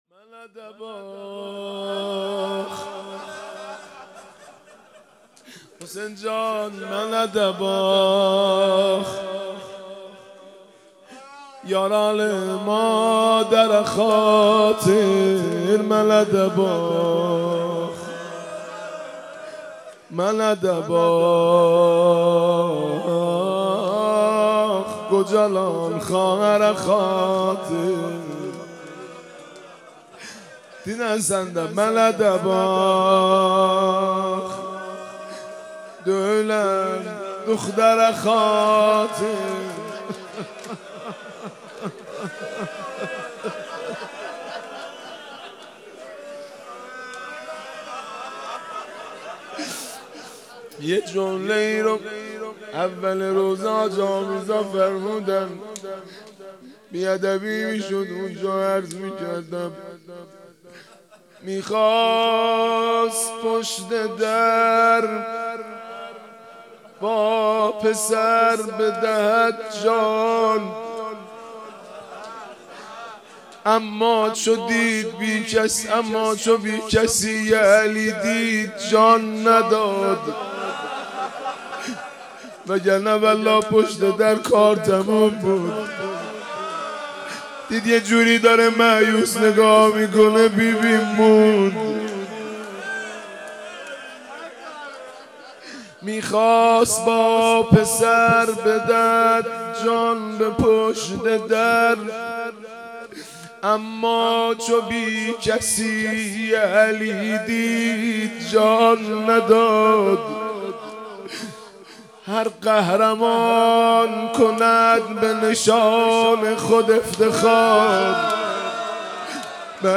مناجات خوانی و مداحی حاج مهدی رسولی در شب بیست و پنجم ماه رمضان
دانلود مناجات خوانی و مداحی زیبا و دلنشین از حاج مهدی رسولی در شب بیست و پنجم ماه رمضان